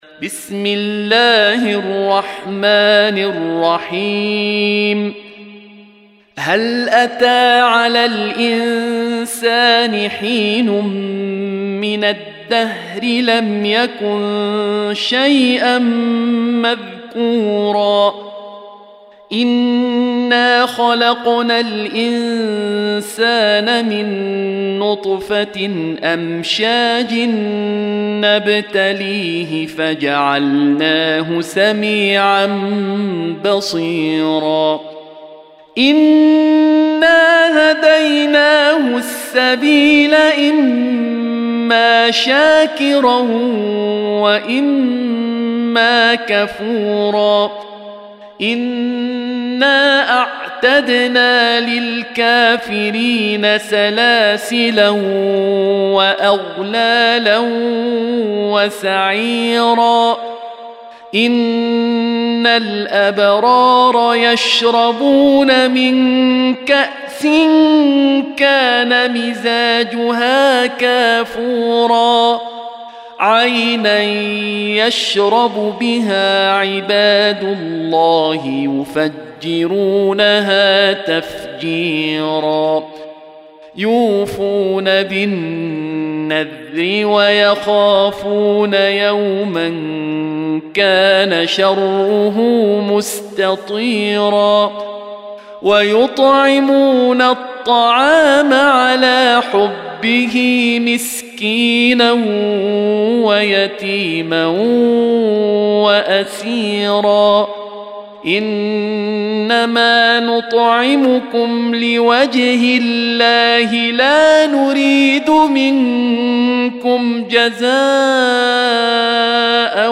Surah Sequence تتابع السورة Download Surah حمّل السورة Reciting Murattalah Audio for 76. Surah Al-Ins�n or Ad-Dahr سورة الإنسان N.B *Surah Includes Al-Basmalah Reciters Sequents تتابع التلاوات Reciters Repeats تكرار التلاوات